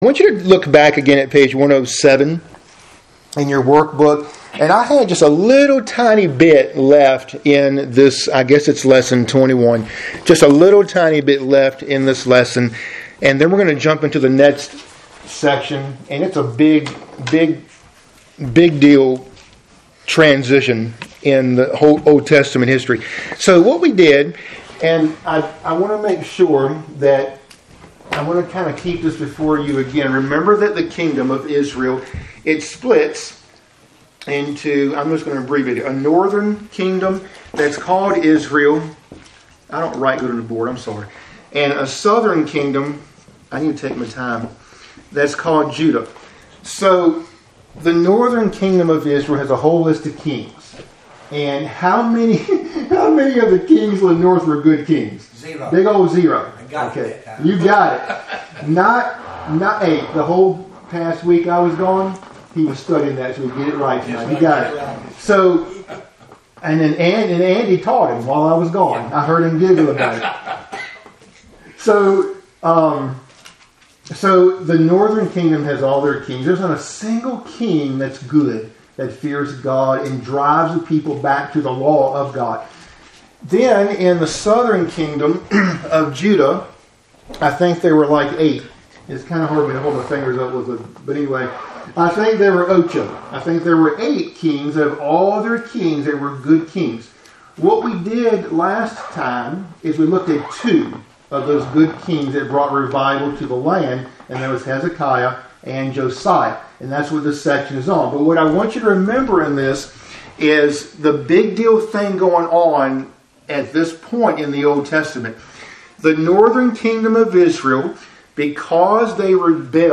Midweek Bible Study – Lessons 21 & 22